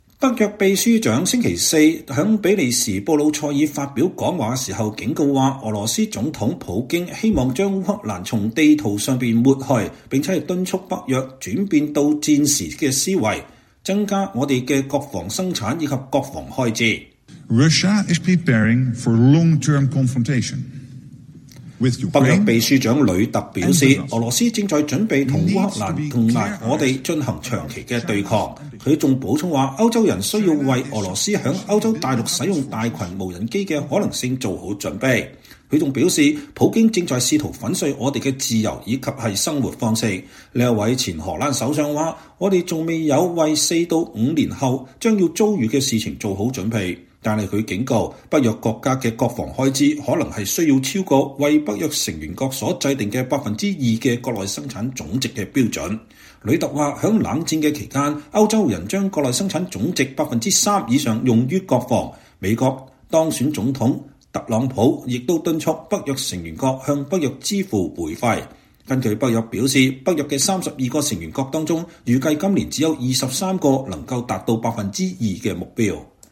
北約秘書長星期四(12月12日)在比利時布魯塞爾發表講話時警告說，俄羅斯總統弗拉基米爾·普京(Vladimir Putin)希望“將烏克蘭從地圖上抹去”，並敦促北約“轉變到戰時思維，增加我們的國防生產和國防開支。